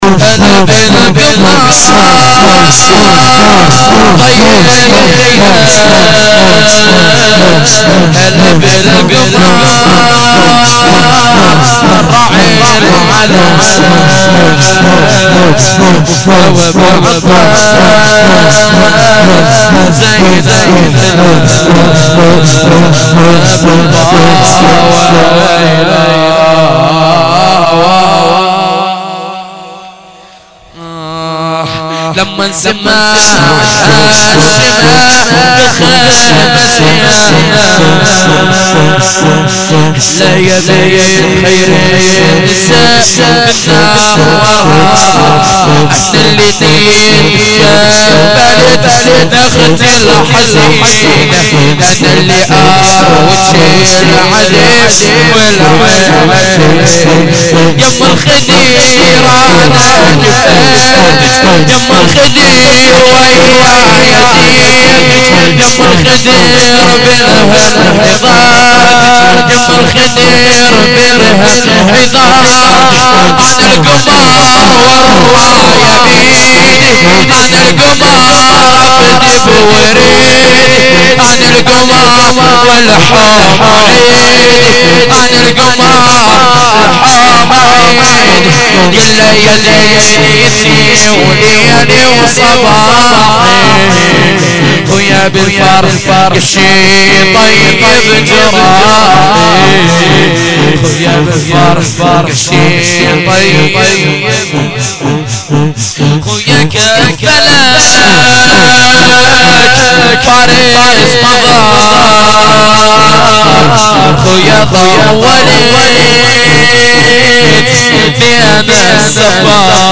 fatemieh-aval-92-shab1-shor-arabi.mp3